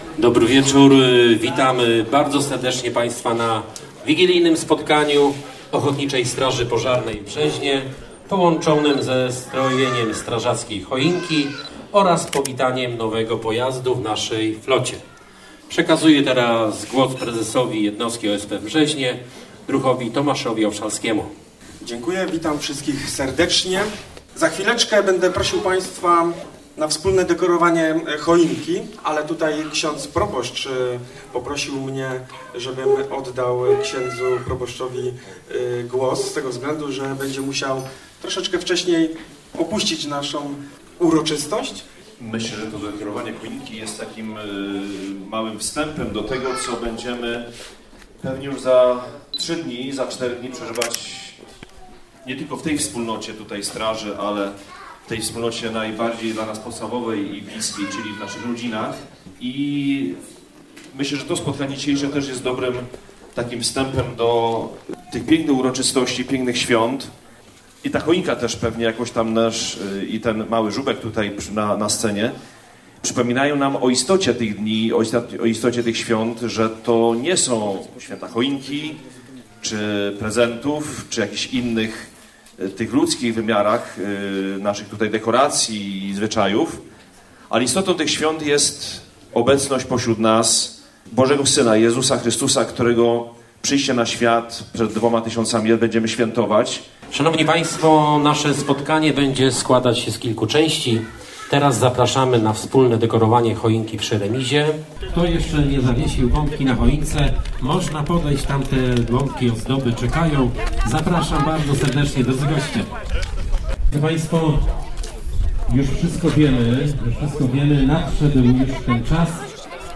Druhny i druhowie z Brzeźna oraz zaproszeni przez nich goście spotkali się dwudziestego pierwszego grudnia w sali widowiskowej Gminnego Ośrodka Kultury.
Głos zabrali też niektórzy zaproszeni goście, składając zebranym świąteczne życzenia.